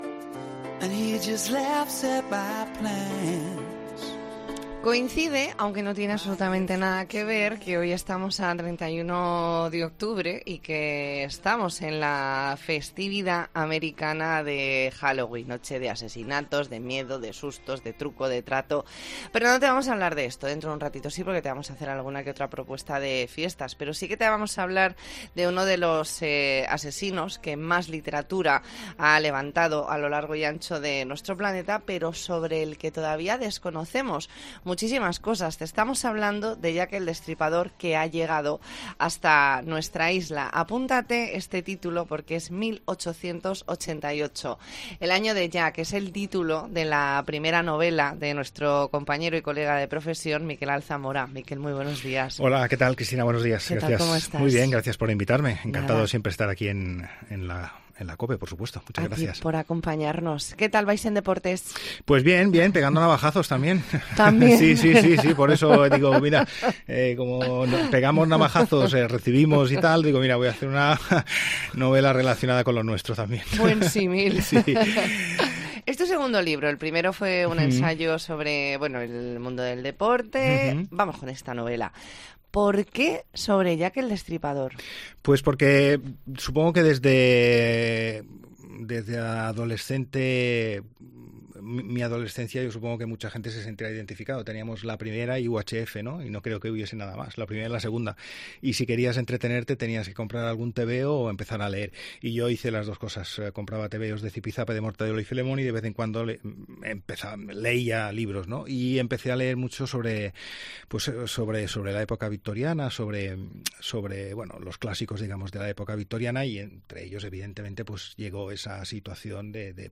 Entrevista en La Mañana en Baleares, lunes 31 de octubre de 2022.